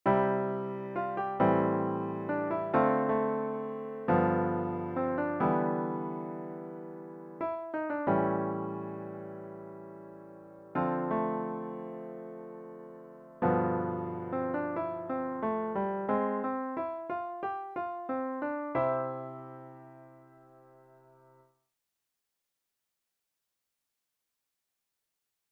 要は最後のＧ７が来た時にＣに戻らずに　Ｅｍ７　へいって　Ａｍ７　Ｄｍ７　Ｇ7
最後にＣのトニックで終了という形です
Ｆｍ(ブドミナントマイナー)を経由して最後に両手でＣを弾くという形にするといい感じに終われます。